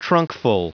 Prononciation du mot trunkful en anglais (fichier audio)
Prononciation du mot : trunkful